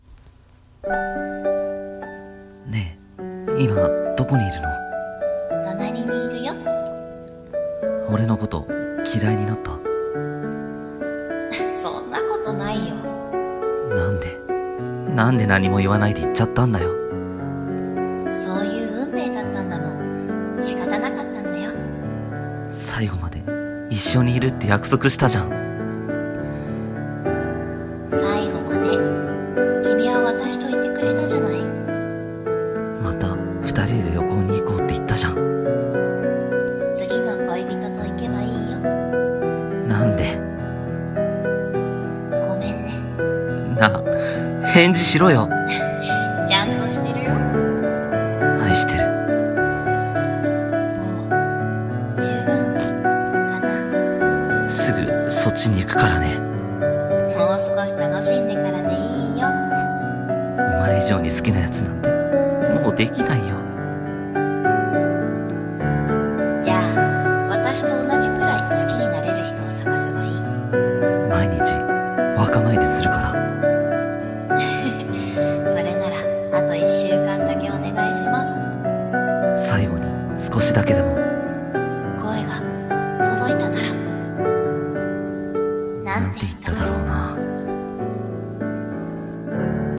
【二人声劇コラボ用】 最後の最後に